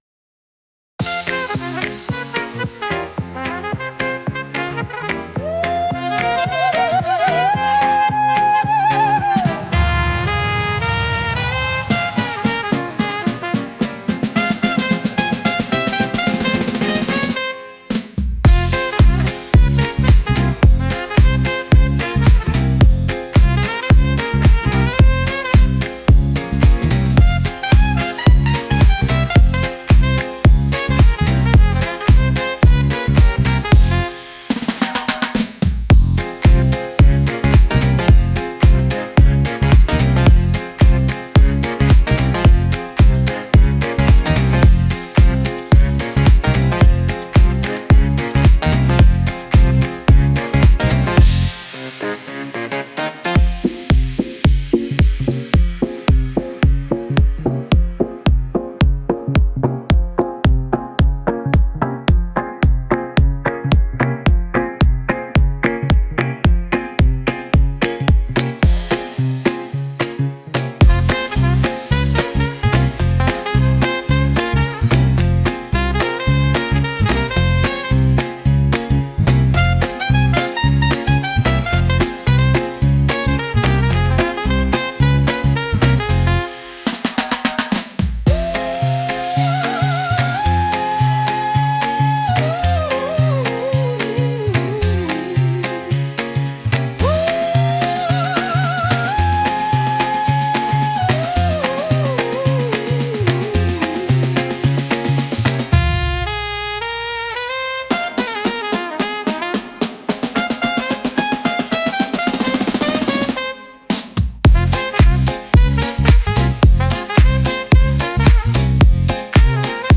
軽やかなダンスビートとスウィング感のあるリズムに、透明感あふれる女性ボーカルが重なる爽快な一曲。